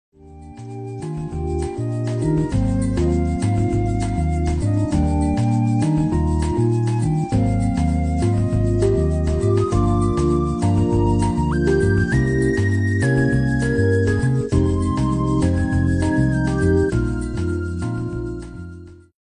Что за лид/синт?
На одной мелодии он начинается на 9 секунде, а на другой на 5 секунде, такой типо уиииуиу